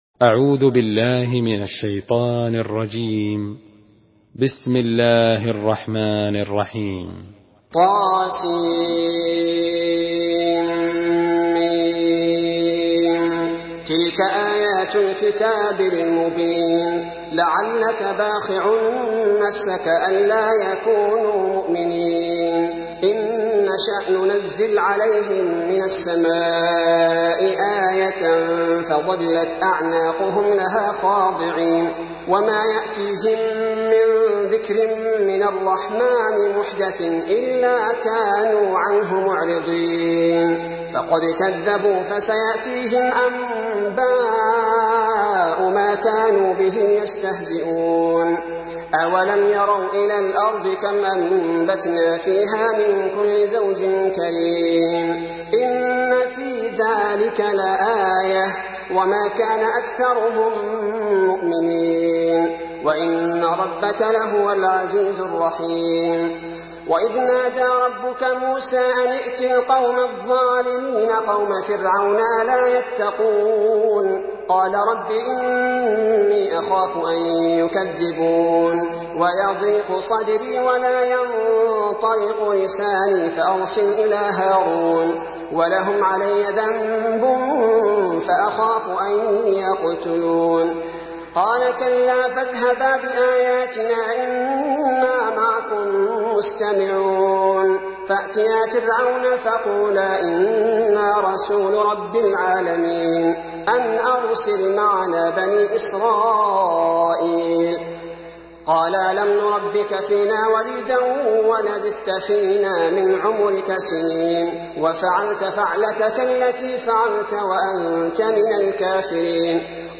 Surah Ash-Shuara MP3 by Abdul bari al thubaity in Hafs An Asim narration.
Murattal Hafs An Asim